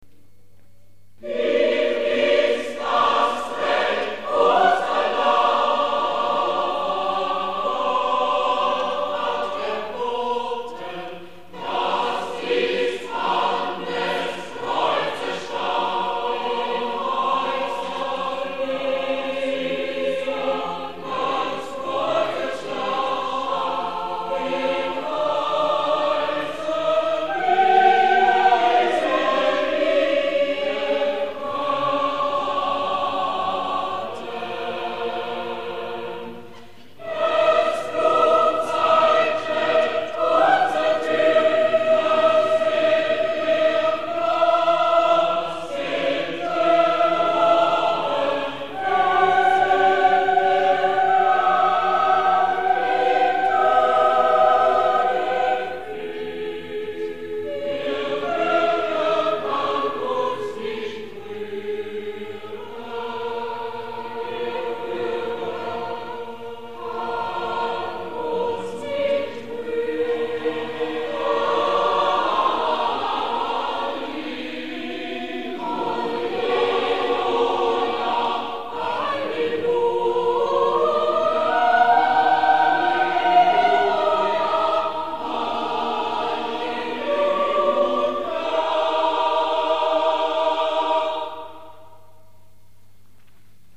für drei Solisten (Evangelist [Ev; Bariton], Tenor und Bass) und drei Chöre a capella
“Hie ist das recht Osterlamm” 2. und 3. Chor